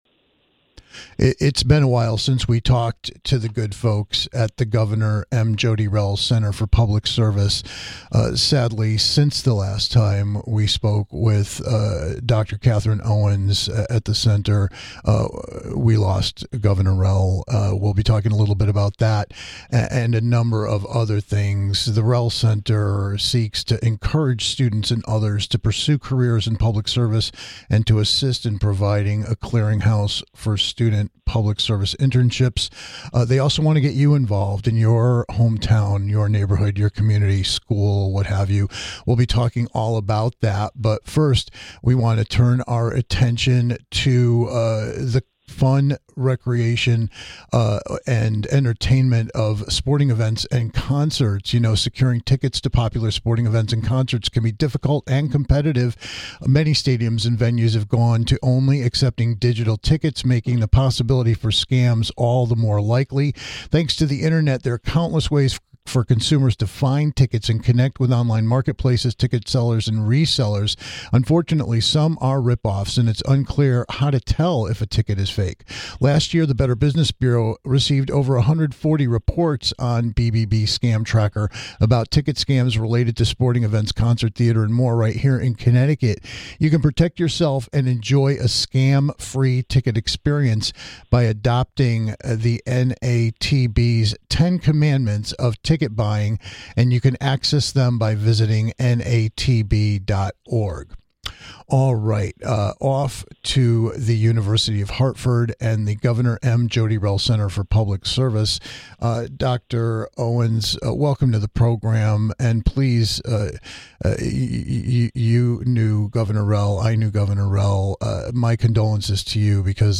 We're living in interesting political times, so we'll tap into a conversation with the Governor M. Jodi Rell Center for Public Service that will help you better appreciate why your voice and presence in some type of public service may be more critical today than ever.